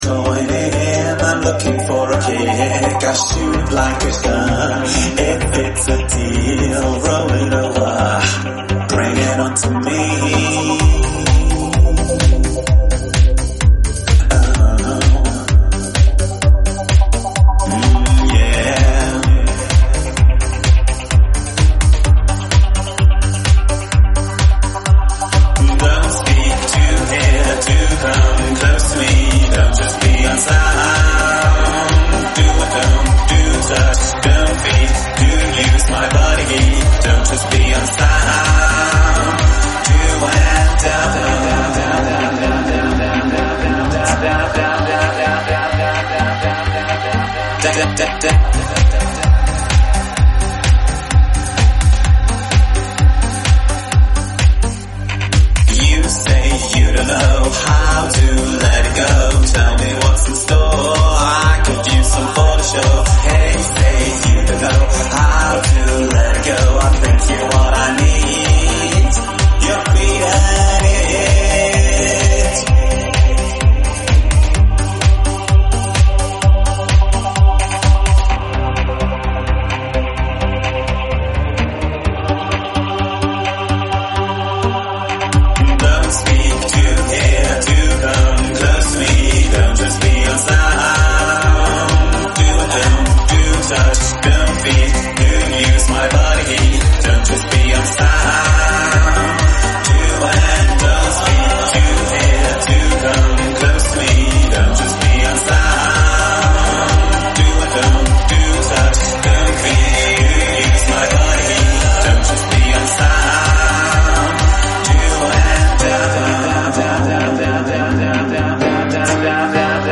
sondern eine mitreißende Reise durch Retro-Klanglandschaften